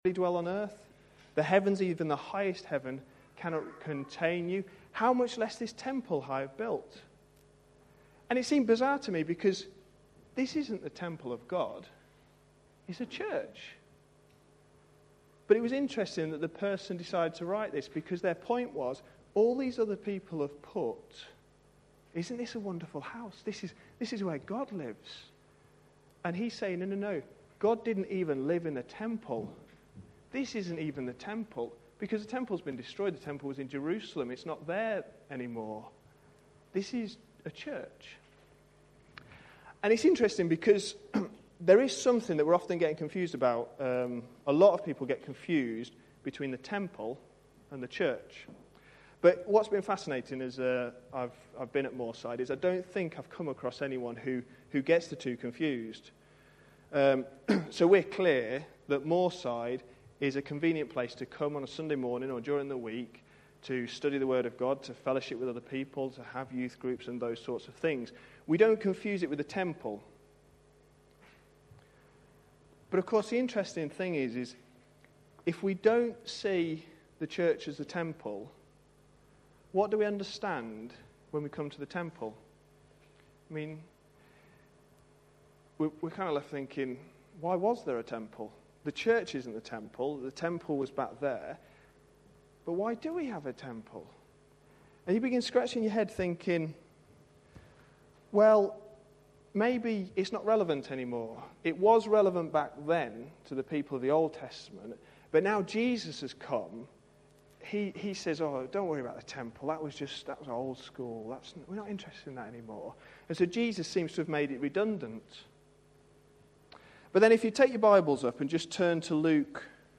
A sermon preached on 3rd April, 2011, as part of our What does the Bible say about... series.